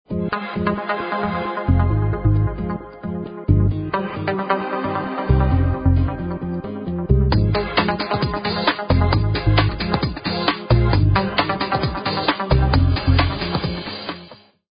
آهنگ تلفن همراه شاد و ورزشی